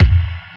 Aunt Wang Syrup Theme Song Kick.wav